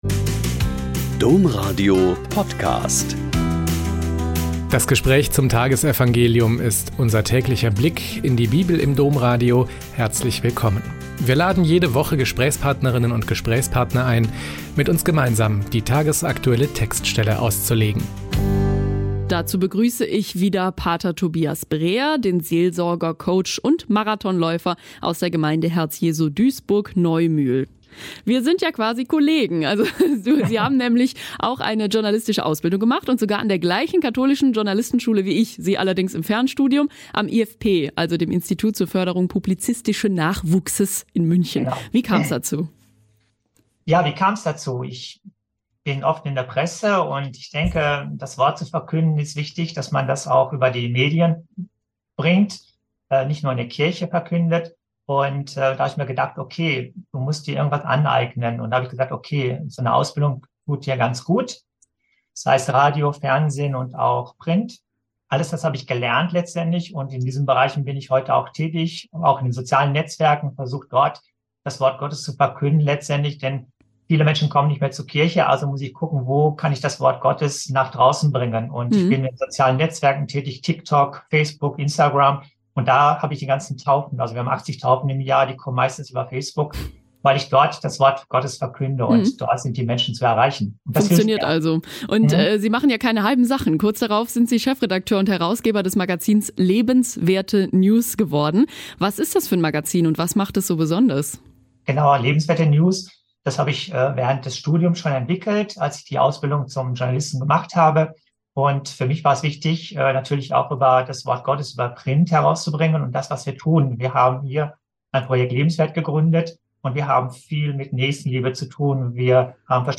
Mt 26,14-25 - Gespräch